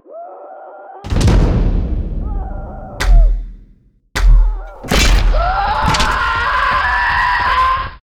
ElevatorSlam.ogg